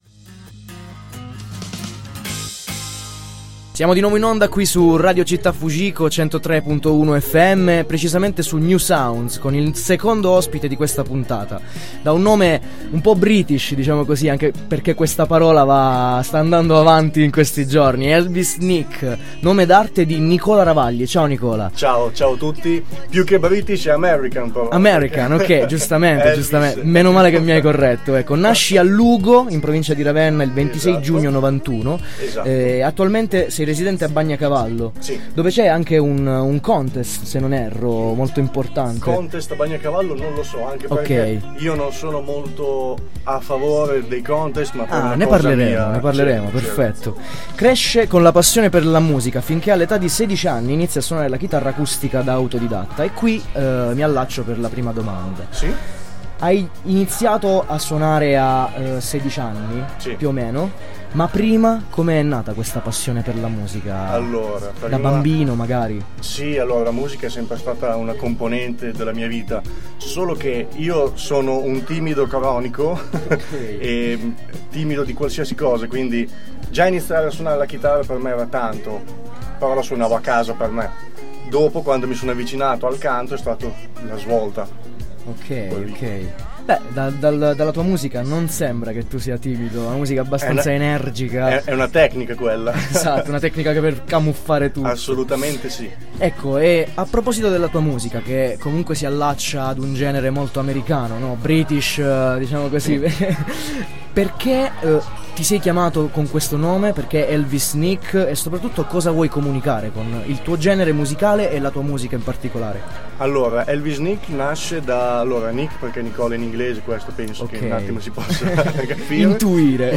Intervista a Radio Città Fujiko 103.1 FM
Intervista andata in onda il 22 Aprile 2018